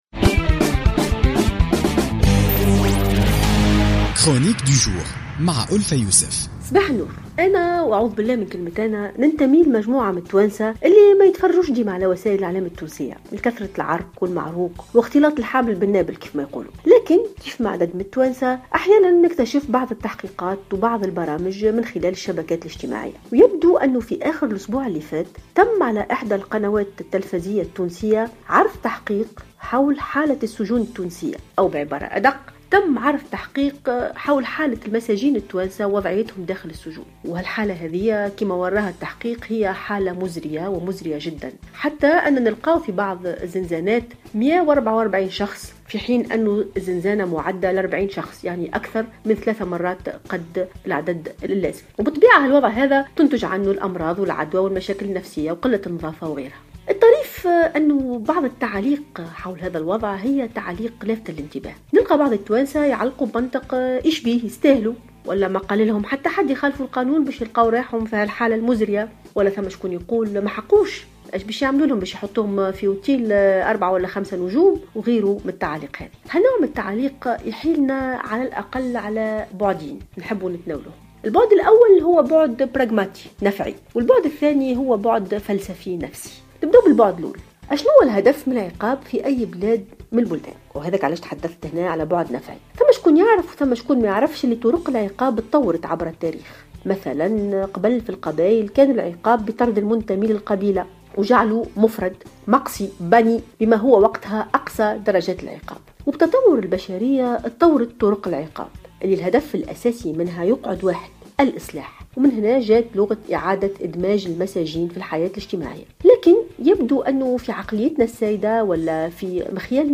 تطرقت الباحثة ألفة يوسف في افتتاحية اليوم الاثنين 25 أفريل 2016 إلى نظرة التونسيين للمساجين من خلال تحقيق أجرته قناة الحوار التونسي وتناقلته مواقع التواصل الاجتماعي والذي أبرز الحالة المزرية جدا التي كانوا عليها داخل الزنزانات اتي فاقت طاقة الاستيعاب المخصصة لها.